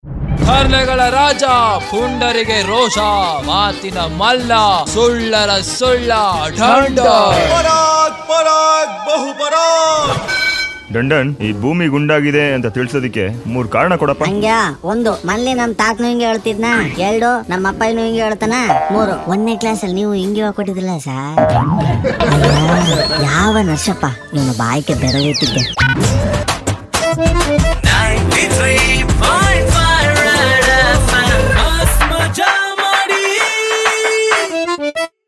Most Loved Comedy Audio Clip That Makes YOU ROFL!!!